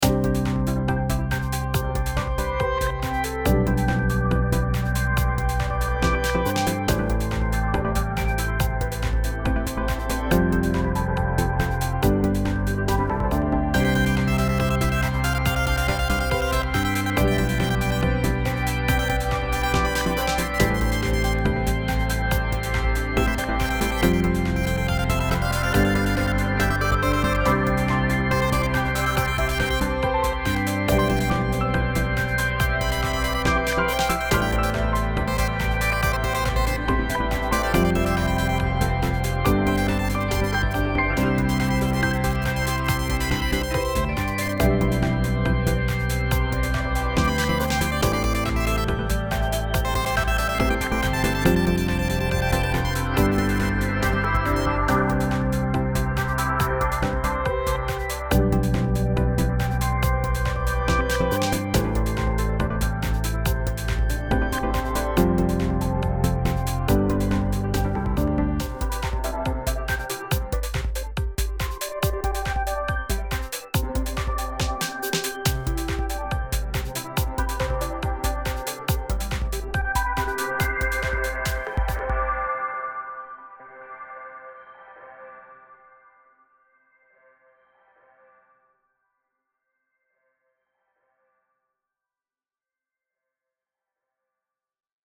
エレクトリック 懐かしい